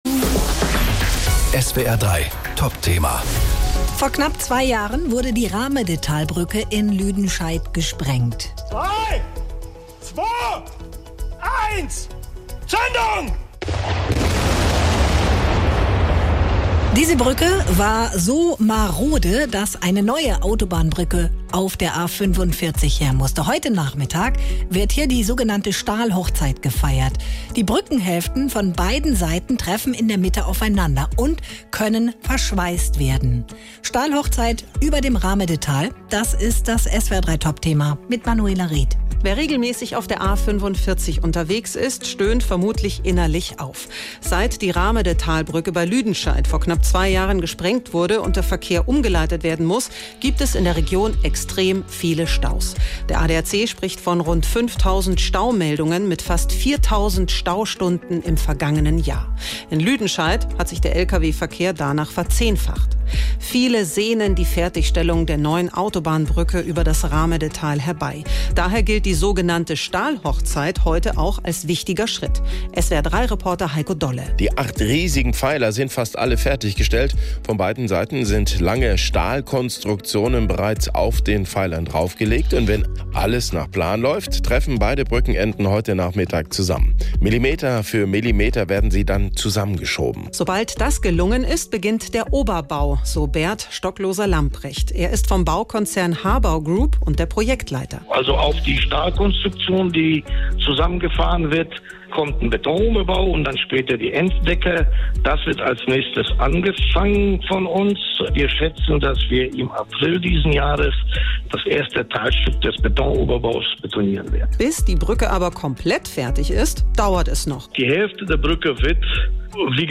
Nachrichten „Stahlhochzeit“ an der Rahmedetalbrücke: „Es geht schnell voran“